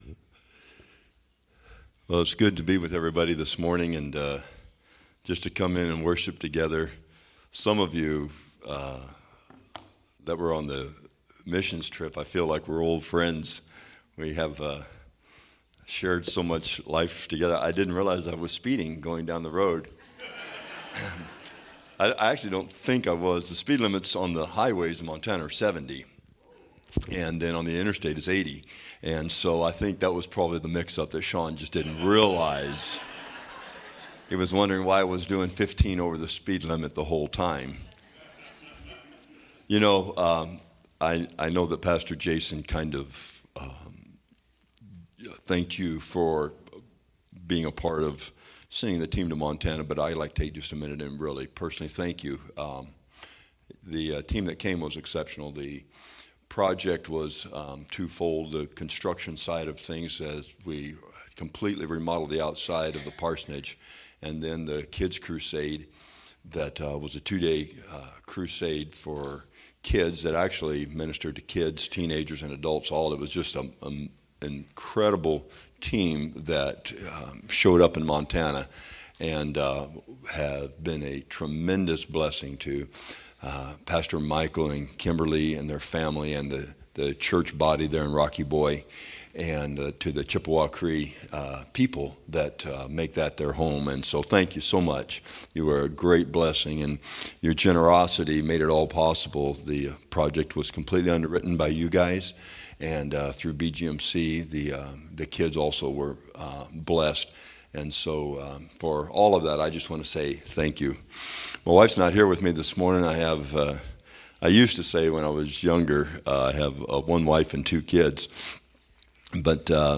Sermons | First Assembly of God Rock Hill
Guest Speaker